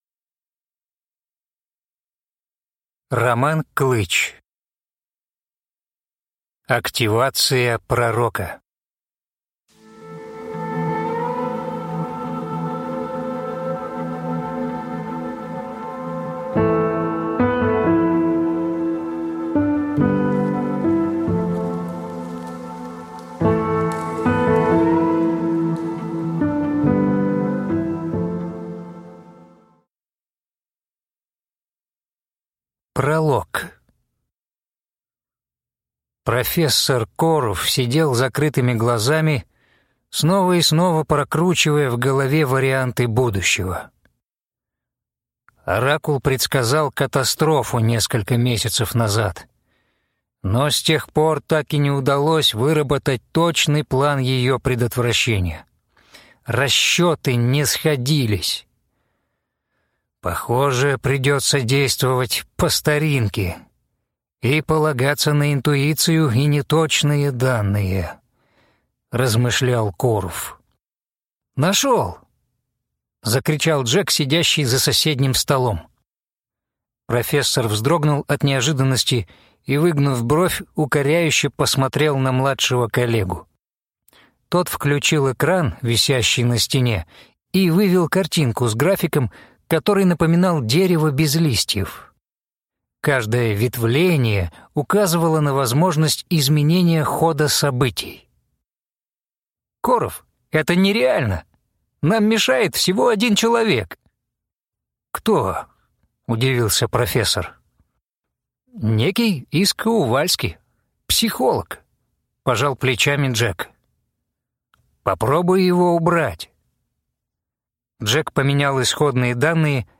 Аудиокнига Активация пророка | Библиотека аудиокниг
Aудиокнига Активация пророка